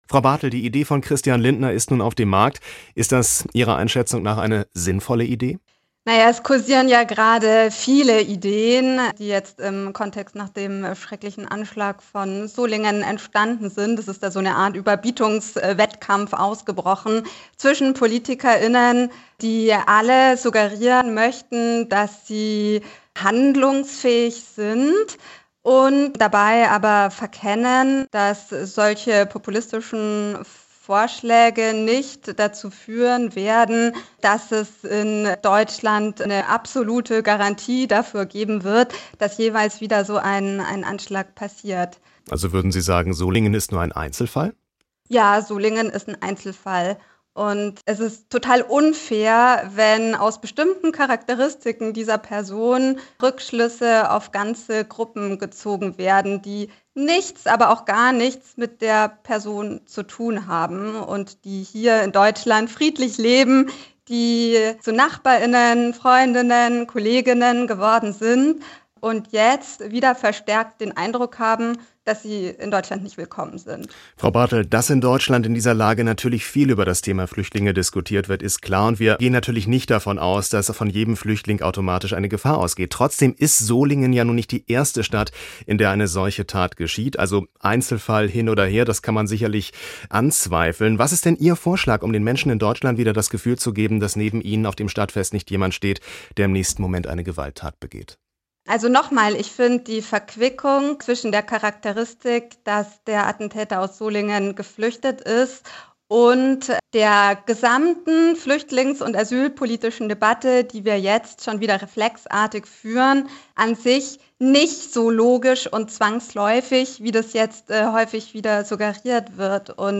3. Radio